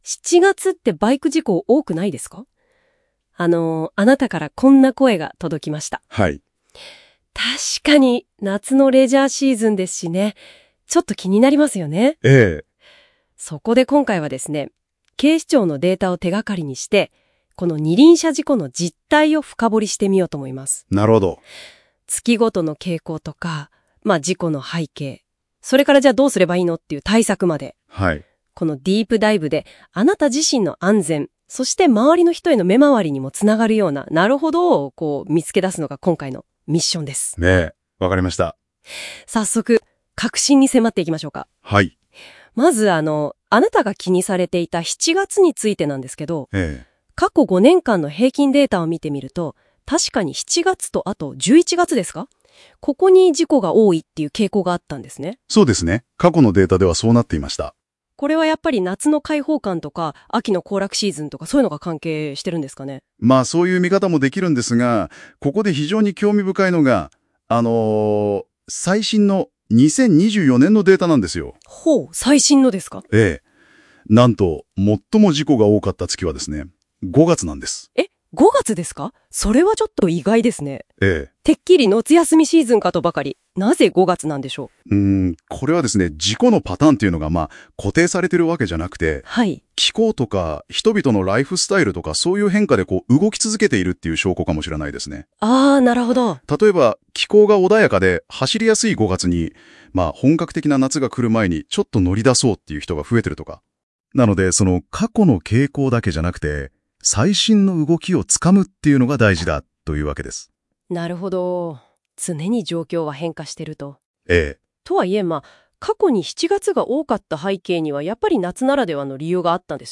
この記事の内容をAIによる音声概要にしています。※漢字の読み間違いなどがあります